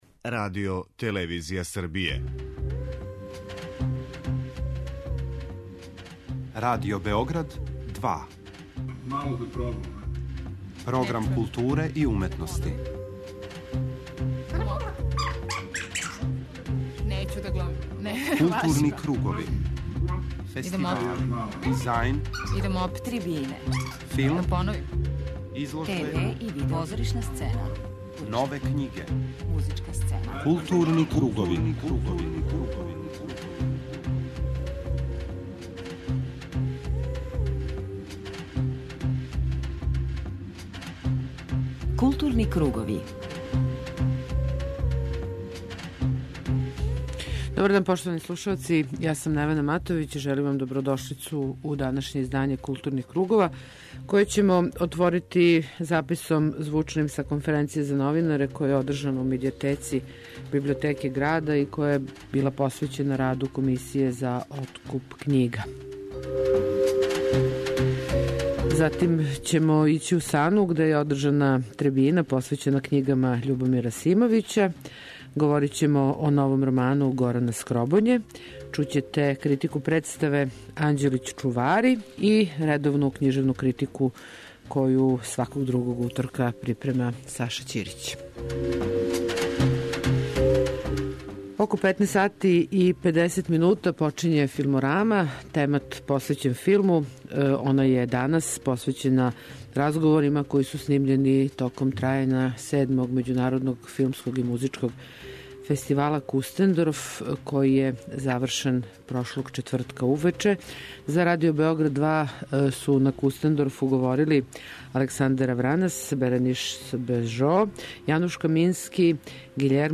У првом делу 'Културних кругова' информисаћемо вас о најзначајнијим догађајима из културе, а у 'Филморами' ћете чути разговоре снимљене током трајања седмог 'Међународног филмског и музичког фестивала Кустендорф'.